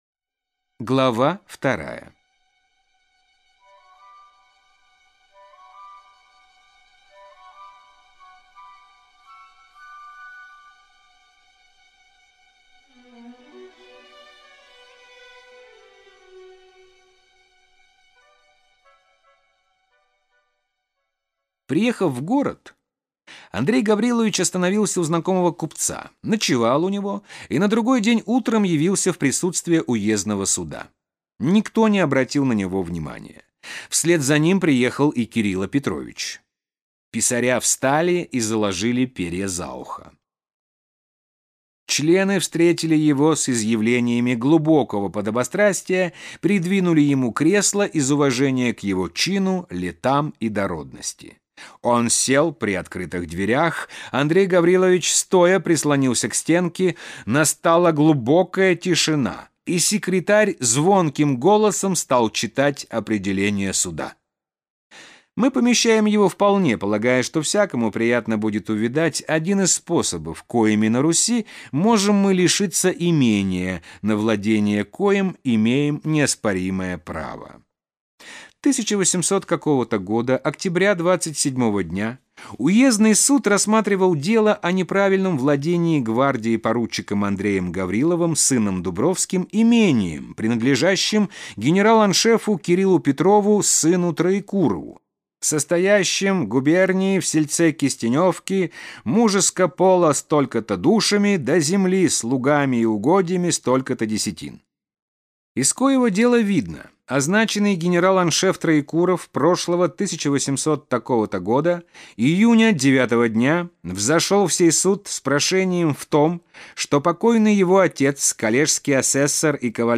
Аудиокнига Повести Белкина. Дубровский | Библиотека аудиокниг